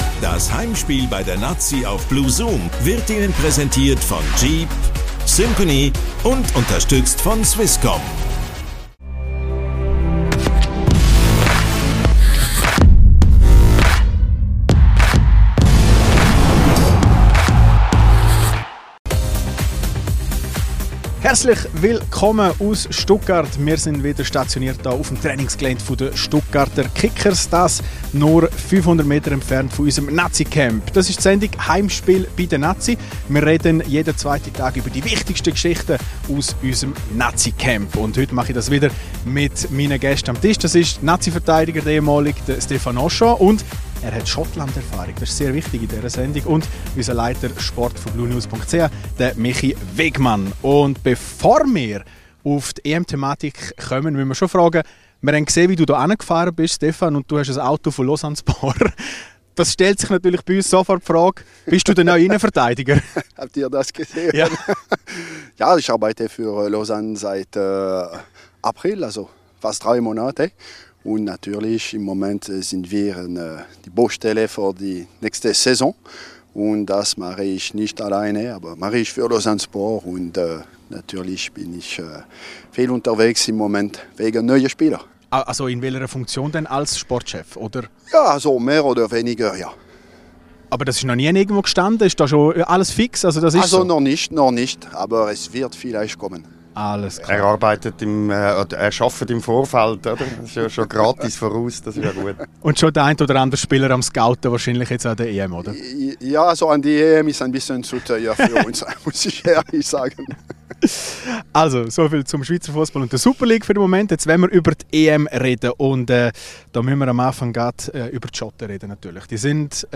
Heimspiel bei der Nati – der EM-Talk von blue Sport.